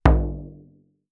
drum_hit.wav